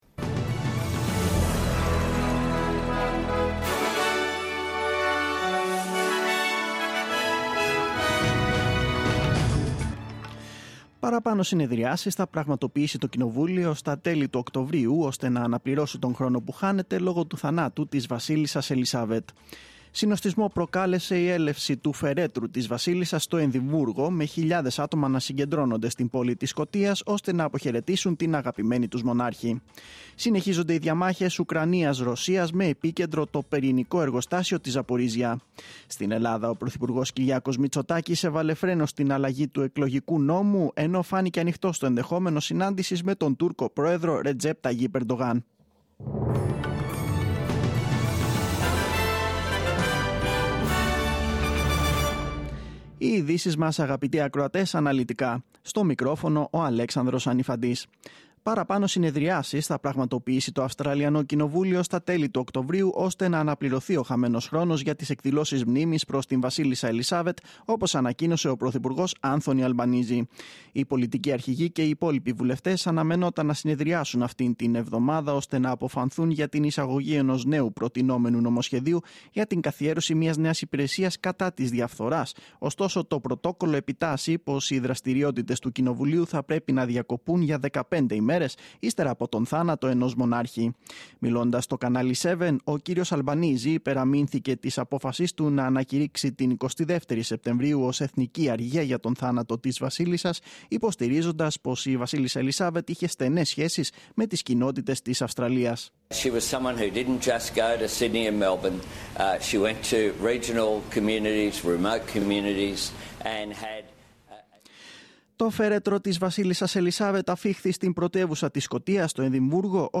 News in Greek.